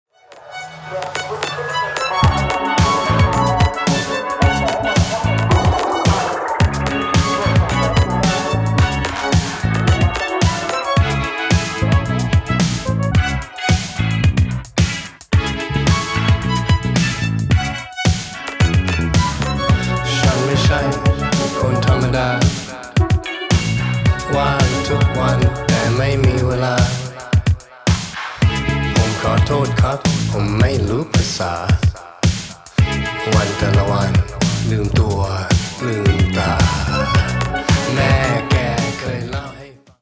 全編に渡って脱力感の漂うベッドルーム・ポップ/シンセ・ファンク/ダウンテンポetcを展開しています。